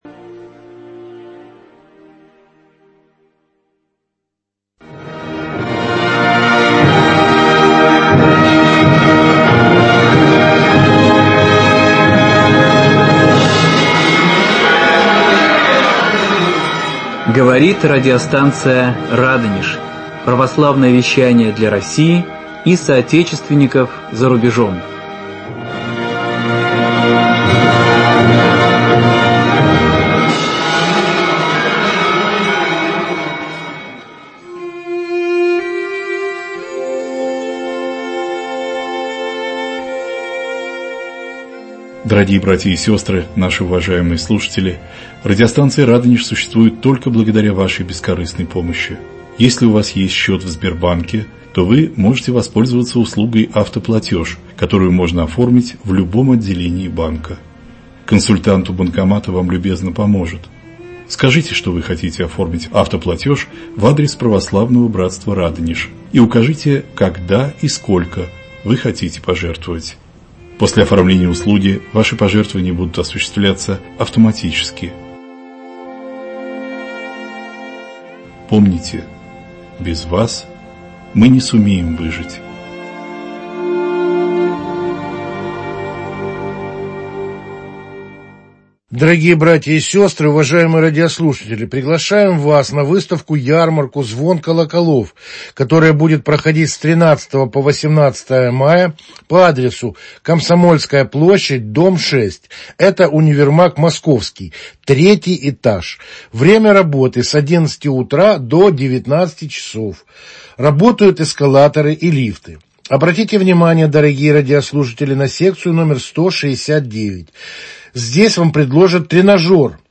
Сегодня в студии радиостанции "Радонеж" О назначении врача в военное и мирное время, о духовных основах медицины беседуют